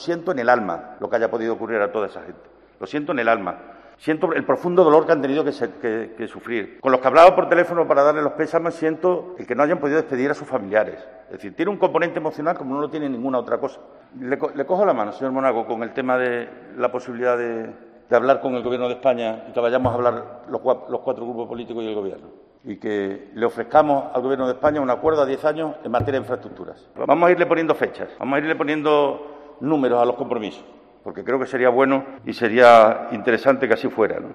AUDIO: El presidente de la Junta, Guillermo Fernández Vara, en el Debate sobre el Estado de la Región.